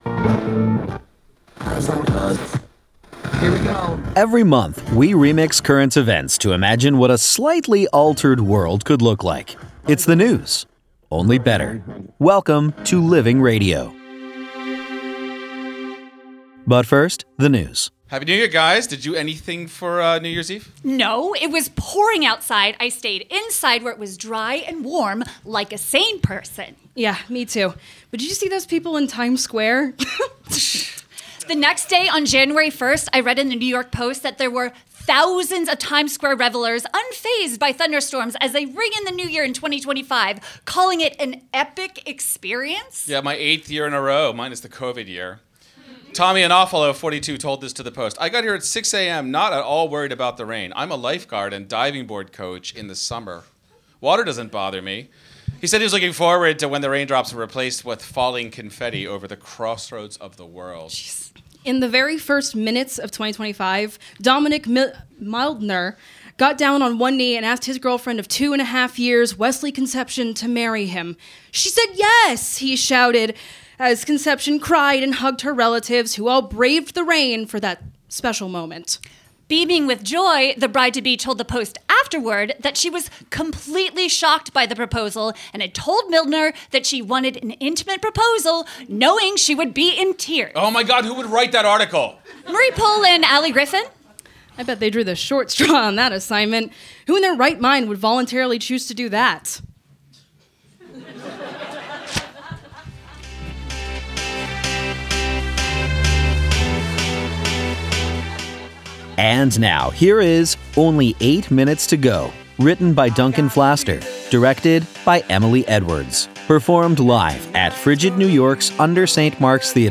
performed live at UNDER St. Mark’s Theater, January 6, 2025